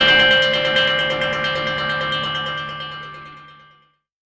GUITARFX 6-R.wav